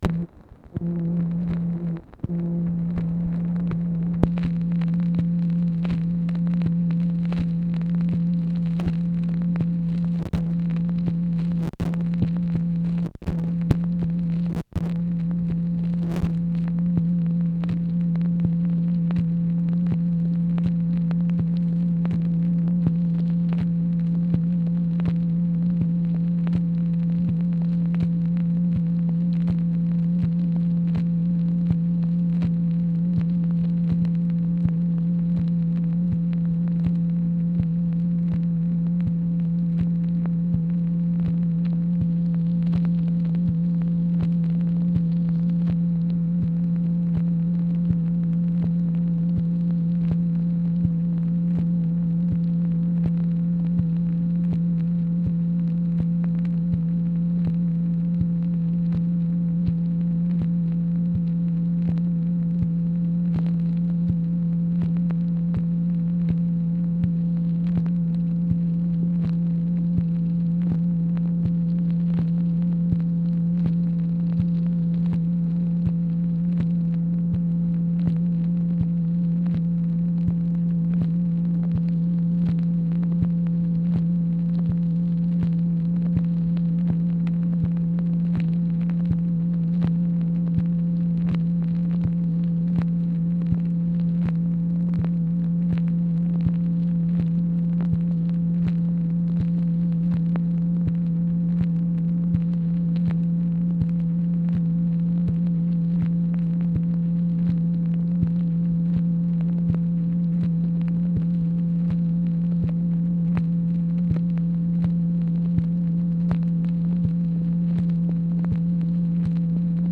MACHINE NOISE, April 7, 1966
Secret White House Tapes | Lyndon B. Johnson Presidency